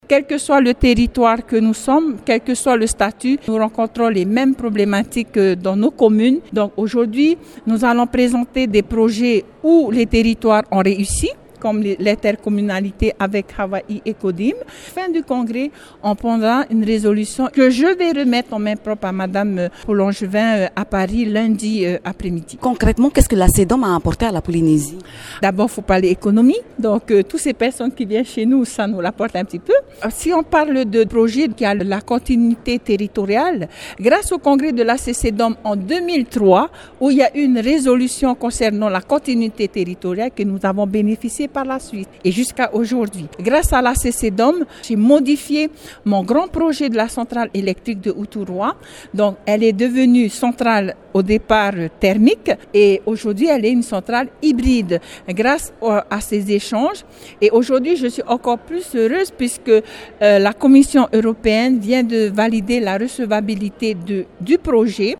Sylviane Terooatea tavana de Uturoa et présidente de l’ACCDOM nous en dit plus